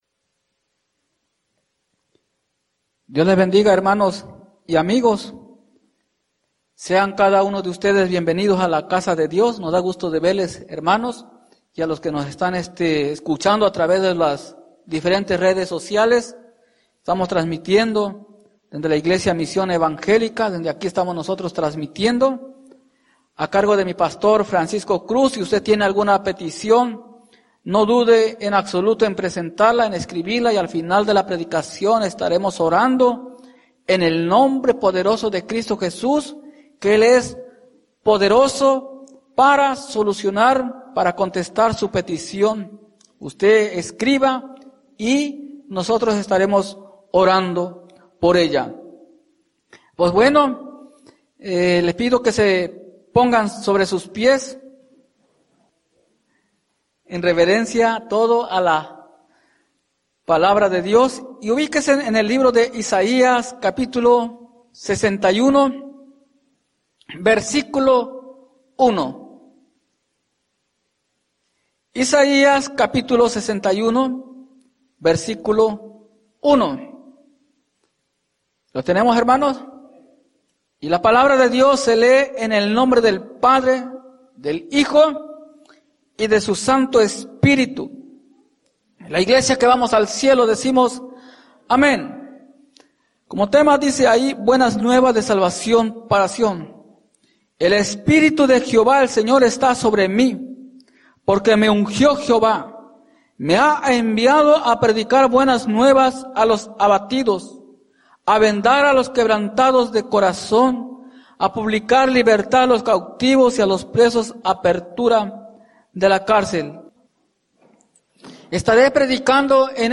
No te quedes en el rapto por falta de aceite Predica